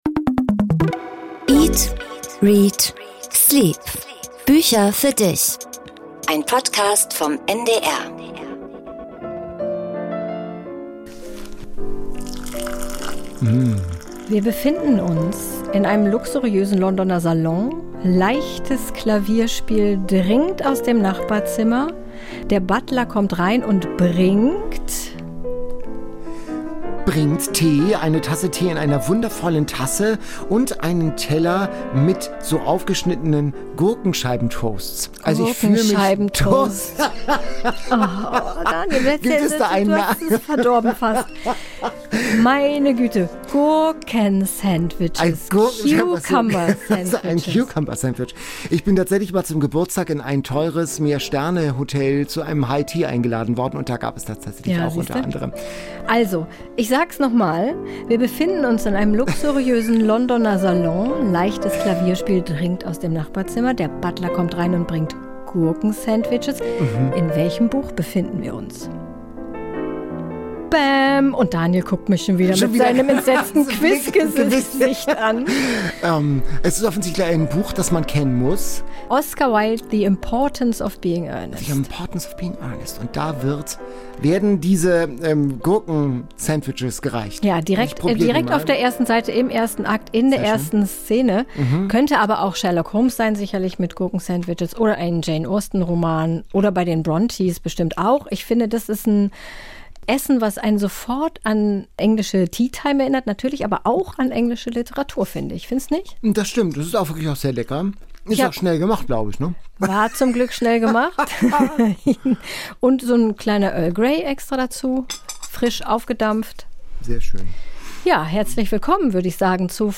Beschreibung vor 5 Jahren Teesalon-Stimmung und ein Starautor Ken Follet, der von Restaurants und Champagner schwärmt gibt es in der Folge 9 des literarischen Podcasts eat.READ.sleep. Ein luxuriöser Londoner Salon, leichtes Klavierspiel dringt aus dem Nachbarzimmer, der Butler kommt rein und bringt Gurkensandwiches.
Zu Gast ist Ken Follett, dessen neuer Roman "Kingsbridge" direkt auf Platz eins der Bestsellerliste landete.